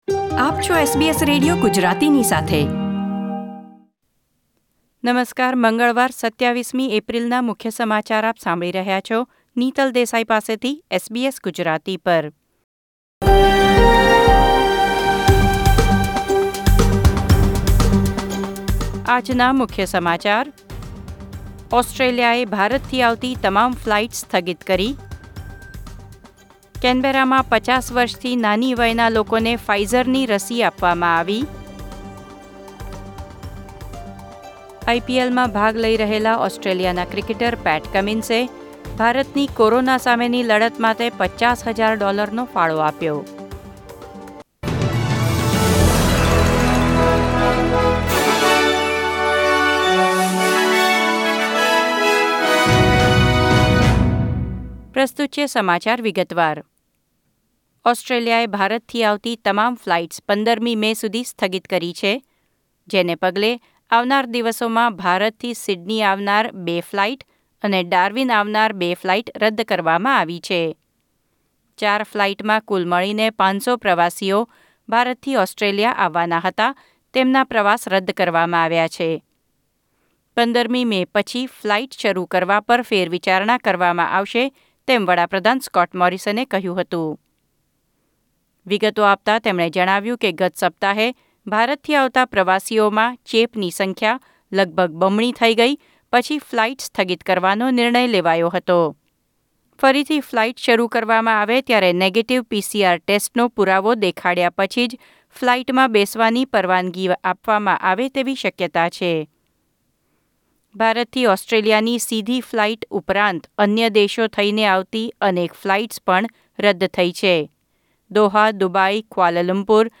SBS Gujarati News Bulletin 27 April 2021